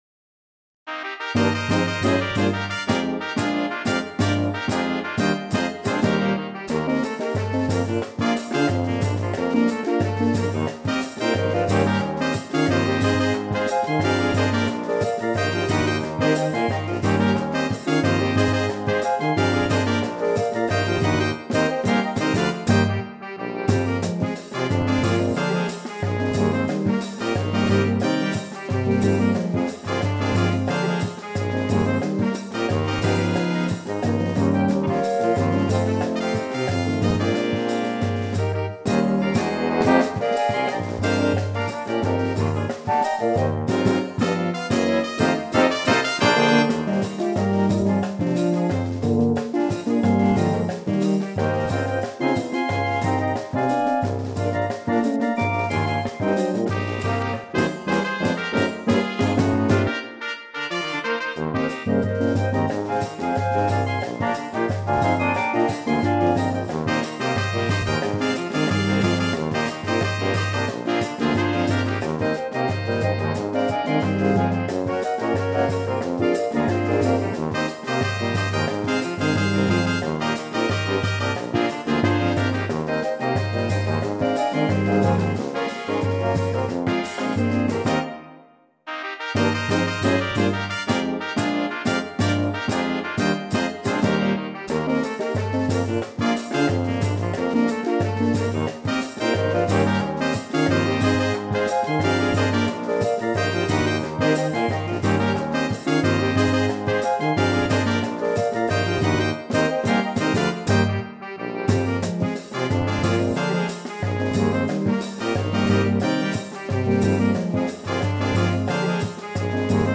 a un rico y variado repertorio de música de banda